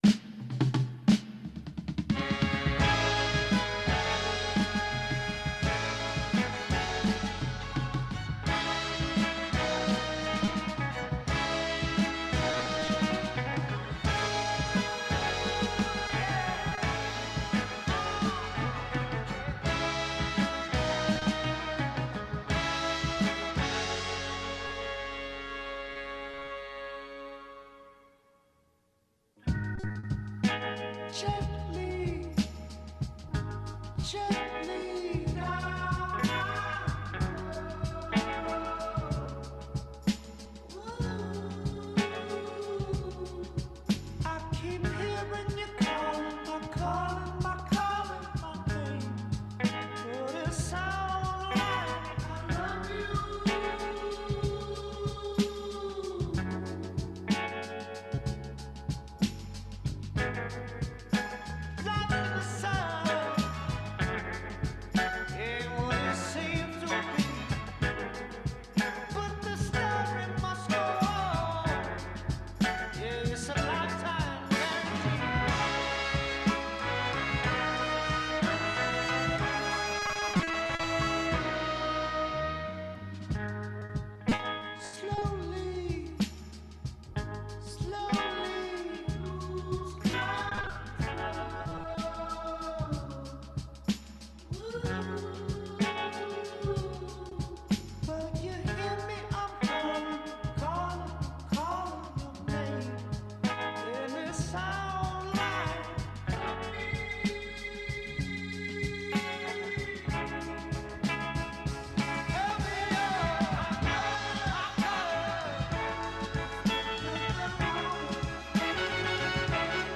Συνέντευξη τύπου του σκηνοθέτη της ταινίας « Τα παιδιά του χειμώνα».Μια ταινία με την οποία ο Πέιν επιστρέφει στην δεκαετία του ΄70 και παρέα με τοναγαπημένο του ηθοποιό Πολ Τζαμάτι, φτιάχνουν την καλύτερη ταινία τους από τον καιρότου υπέροχου « Πλαγίως».